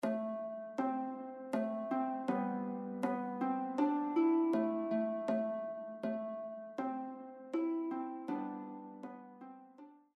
” is a Breton song dating back to the Middle Ages